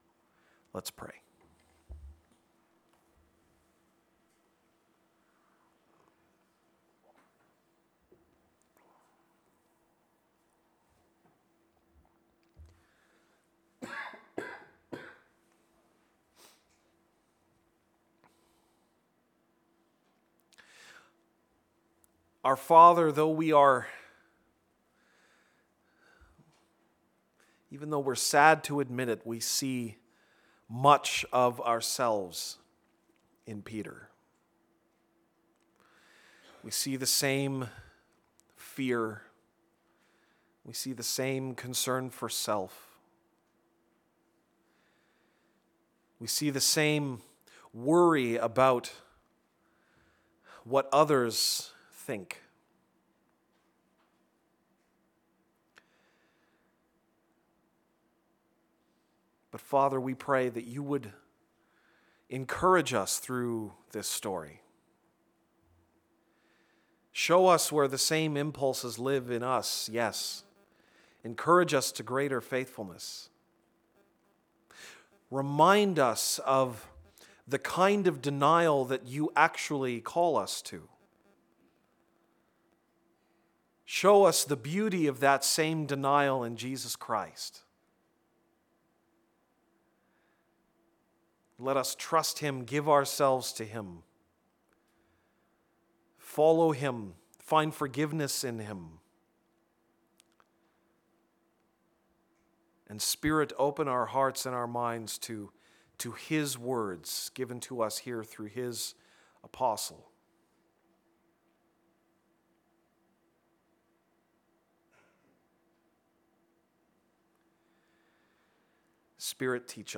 April 23, 2017 (Sunday Morning)